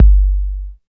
62 808 KICK.wav